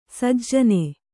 ♪ sajjani